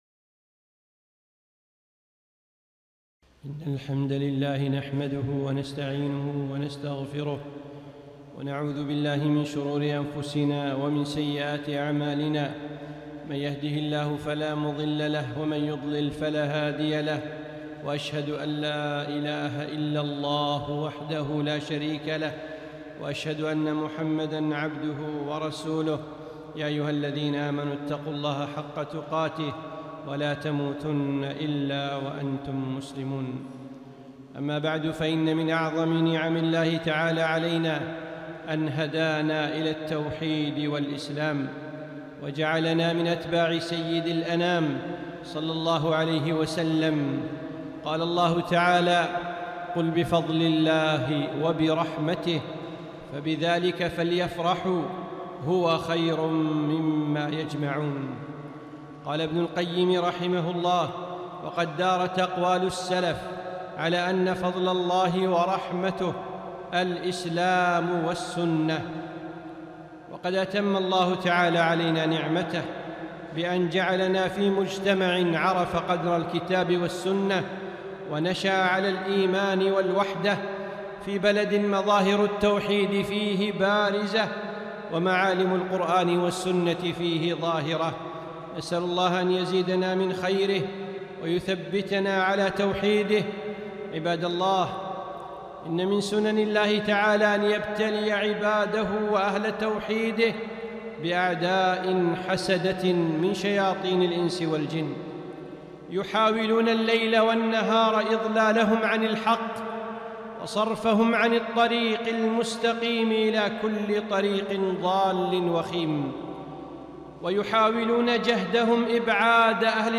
خطبة - احذروا الممارسات الدخيلة